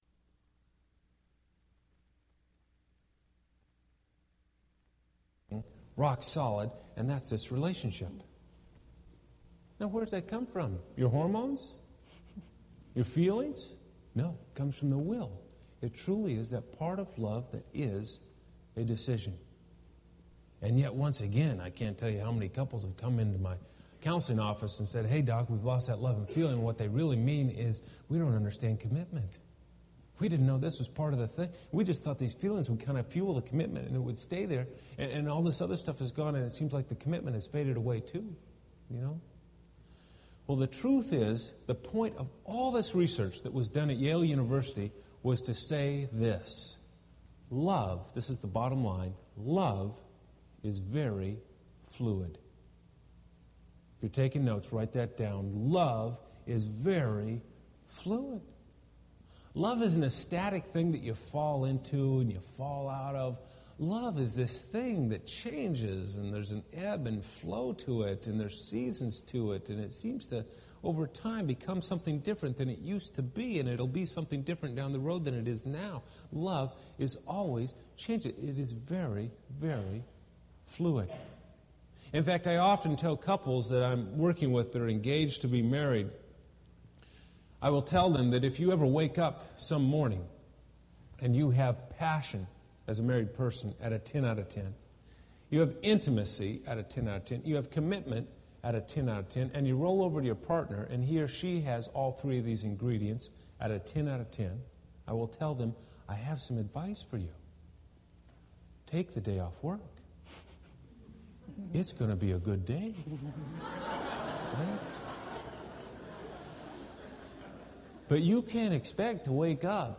Crabtree Family Life Series Chapel: Les & Leslie Parrot
Les & Leslie Parrot , Co-Directors, Center for Relationship Development, Seattle Pacific University Address: What's Love Got To Do With It?